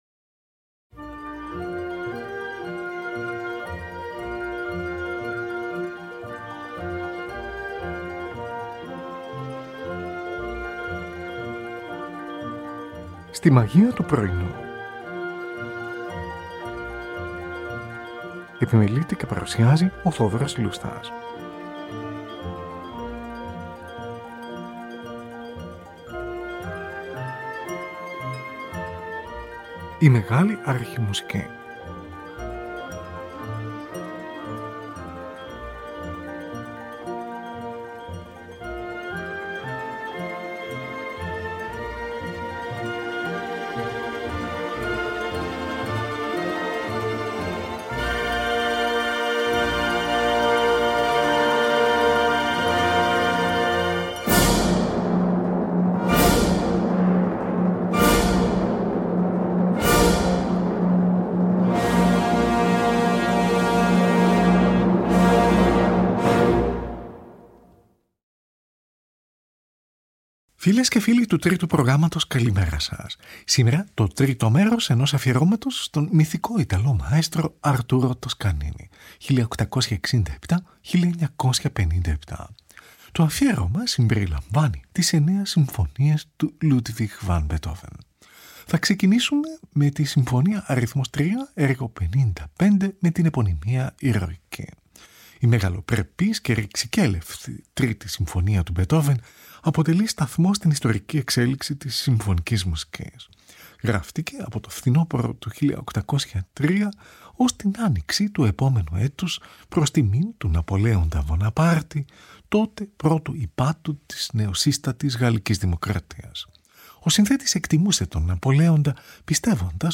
Τη Συμφωνική του NBC διευθύνει ο Arturo Toscanini . Zωντανή ραδιοφωνική μετάδοση, στις 28 Οκτωβρίου 1939 . Ludwig van Beethoven: Ορχηστρική Εισαγωγή, γνωστή ως “Leonore 1”, έργο 138.